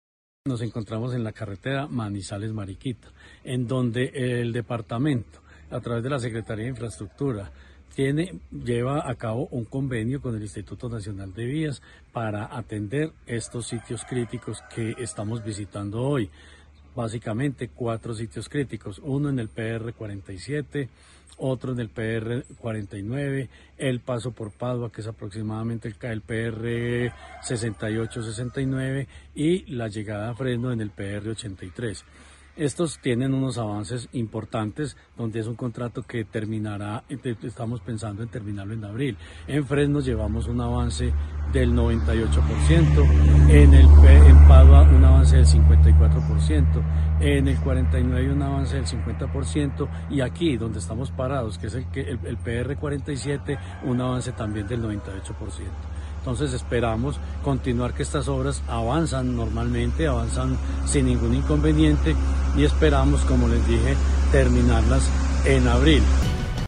Jorge Ricardo Gutiérrez Cardona, secretario de Infraestructura de Caldas.